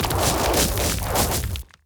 Rock Meteor Swarm 2.ogg